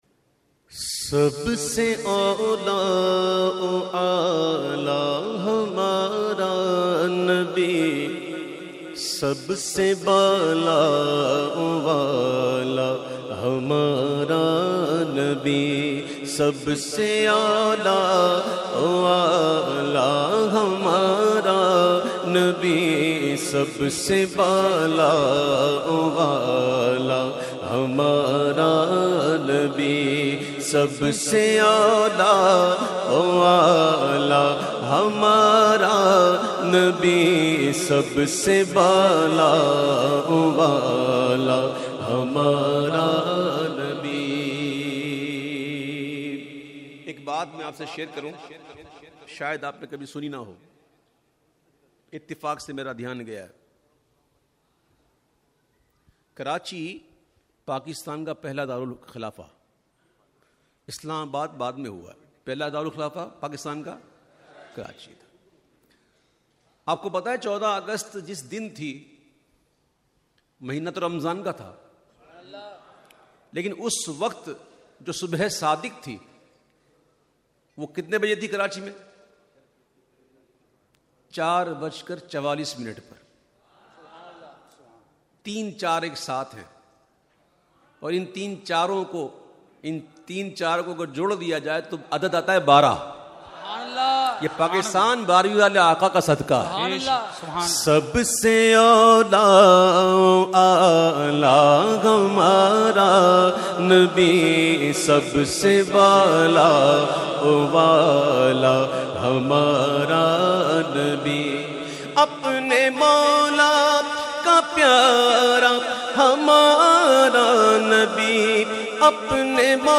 The Naat Sharif Sab Se Aula o Aala Hamara Nabi recited by famous Naat Khawan of Pakistan Owais Qadri.